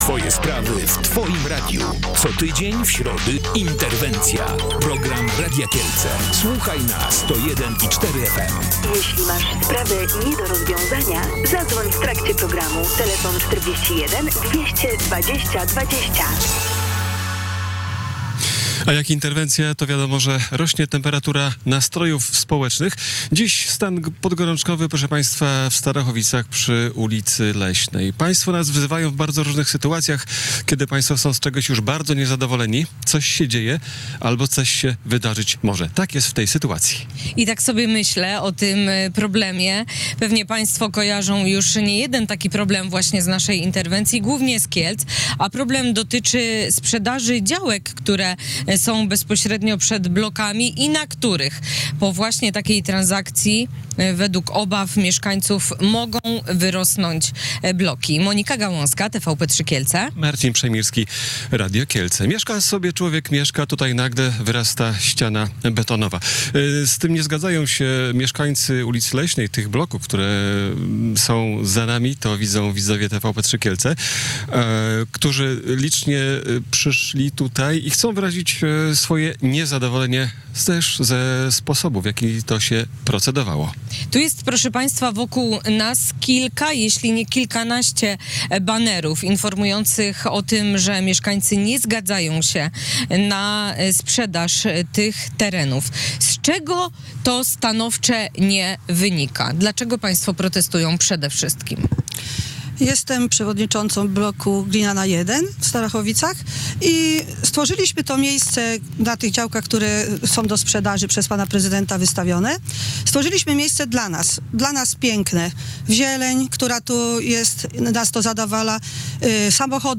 O tym rozmawialiśmy w programie Interwencja.